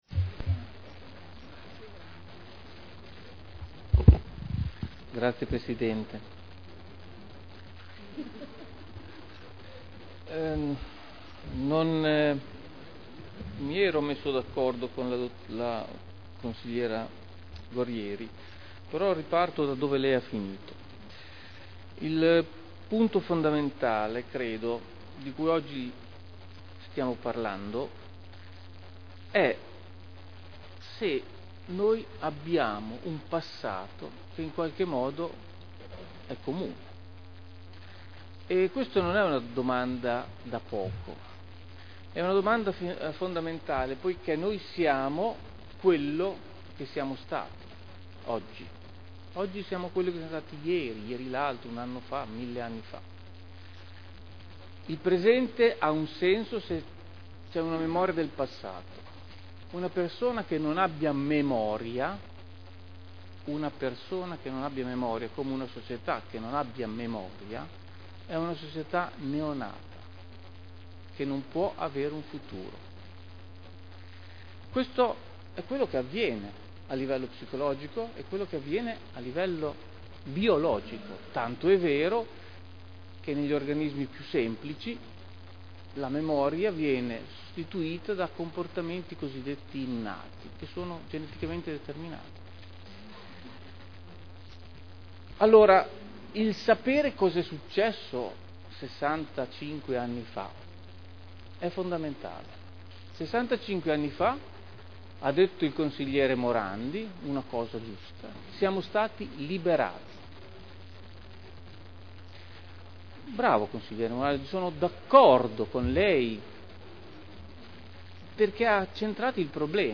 Seduta del 10/01/2011.